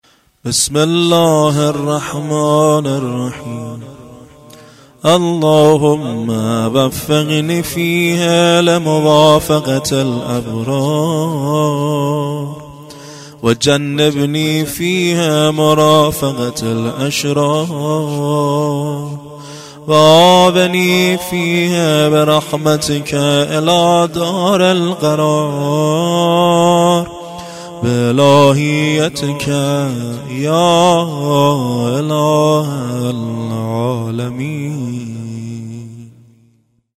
خیمه گاه - هیئت زواراباالمهدی(ع) بابلسر - دعای روزشانزدهم ماه مبارک رمضان